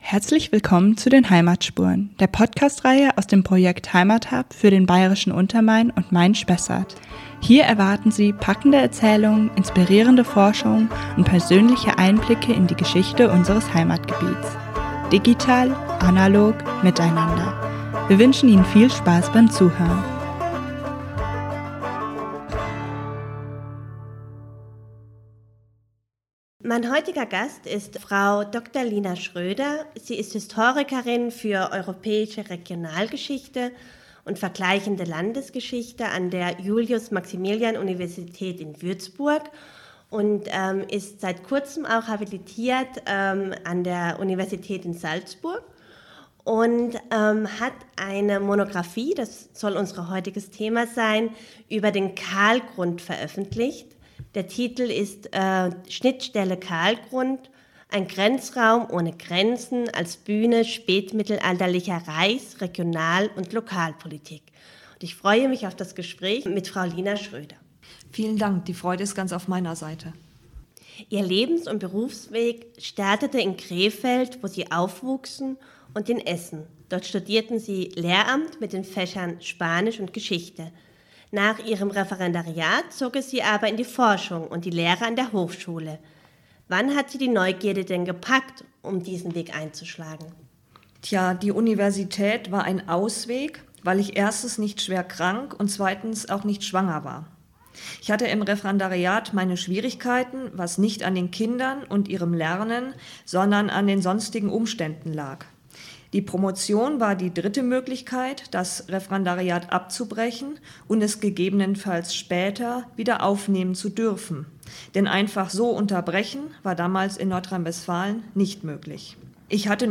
Moderiert wird die Reihe von Archäologin
Die musikalische Umrahmung